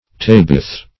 Tebeth \Te"beth\, n. [Heb.]